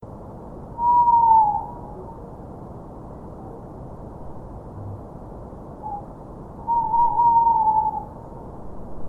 Kodukakk-suvine.mp3